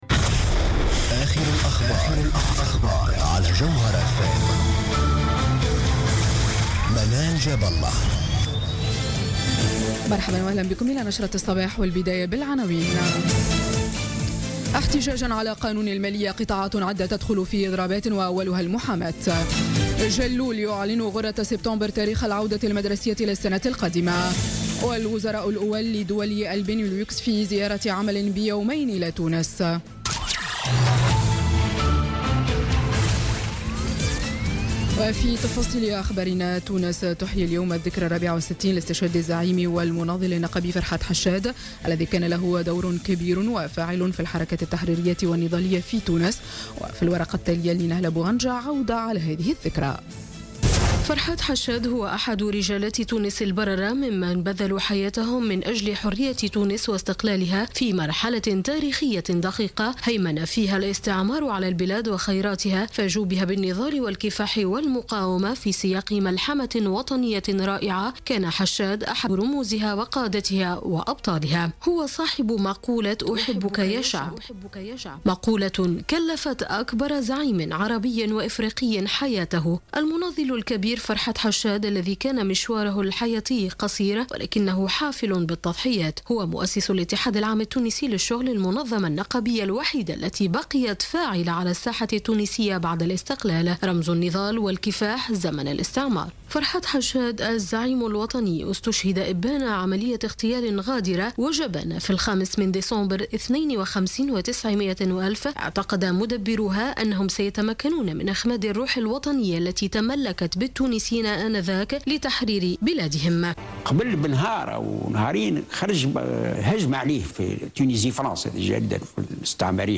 نشرة أخبار السابعة صباحا ليوم الاثنين 5 ديسمبر 2016